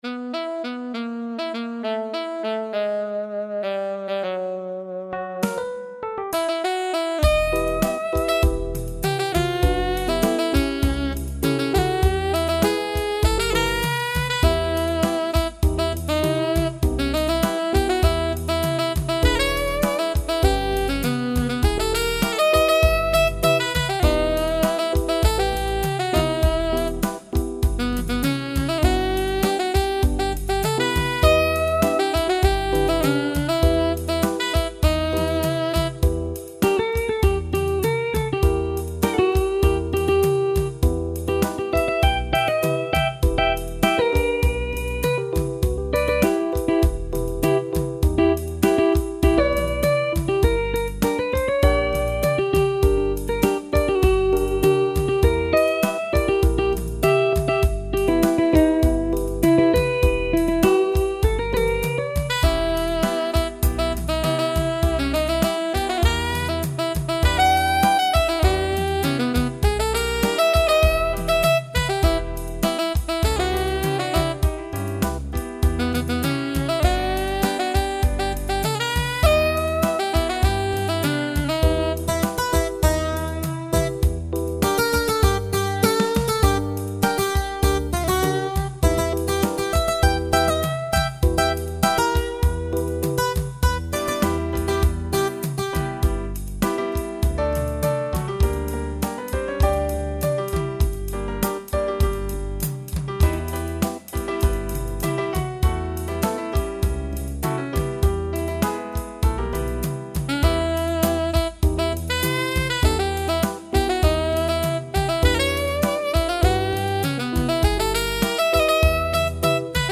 Rural guitar and sax blues from U.S.